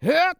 CK蓄力05.wav
CK蓄力05.wav 0:00.00 0:00.40 CK蓄力05.wav WAV · 34 KB · 單聲道 (1ch) 下载文件 本站所有音效均采用 CC0 授权 ，可免费用于商业与个人项目，无需署名。
人声采集素材/男2刺客型/CK蓄力05.wav